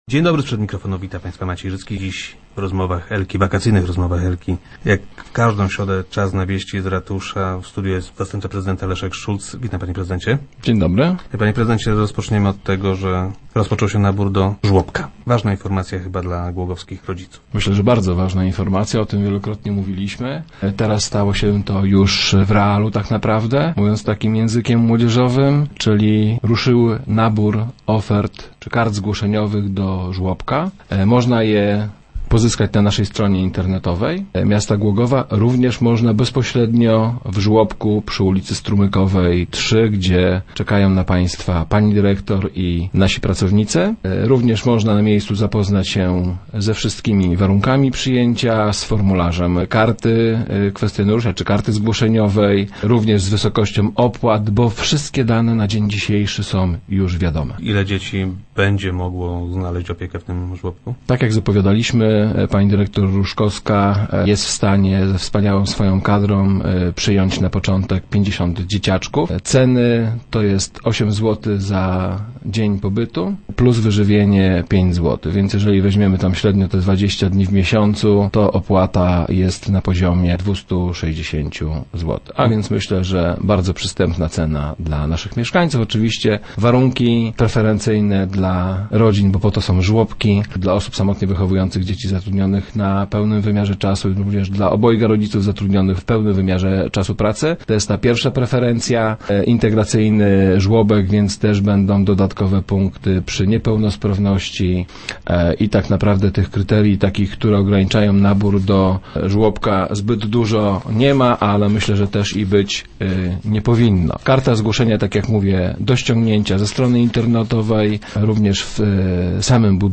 - Ankiety można znaleźć na stronie internetowej urzędu miasta oraz w placówce - informuje Leszek Szulc, zastępca prezydenta Głogowa.